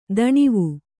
♪ daṇivu